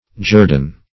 jurdon - definition of jurdon - synonyms, pronunciation, spelling from Free Dictionary Search Result for " jurdon" : The Collaborative International Dictionary of English v.0.48: Jurdon \Jur"don\ (j[^u]r"d[o^]n), prop. n. Jordan.